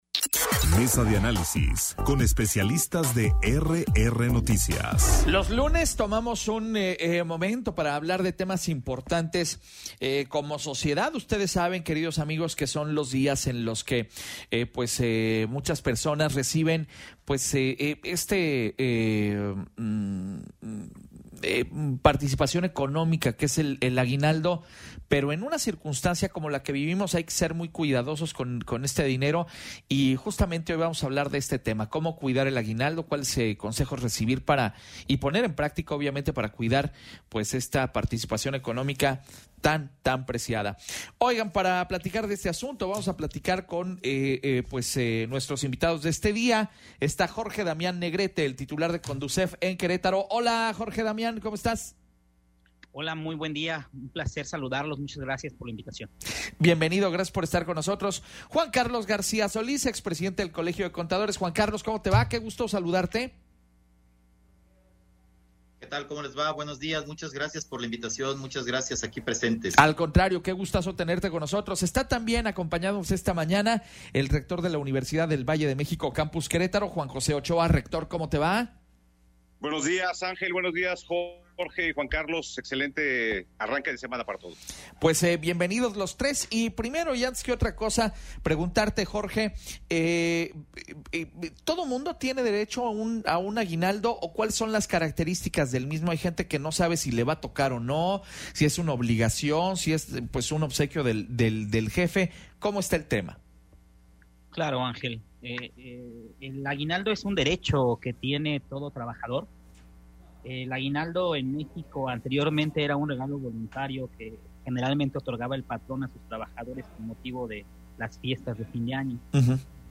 Mesa de Análisis